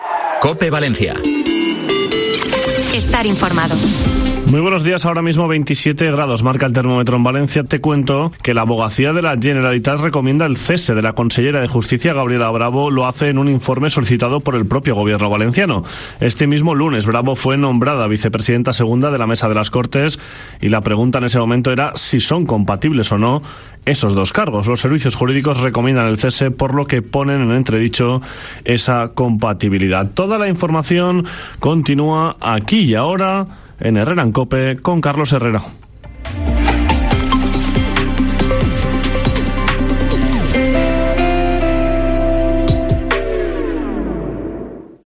AUDIO: Boletín informativo con informe abogacía sobre incompatibilidad de Gabriela Bravo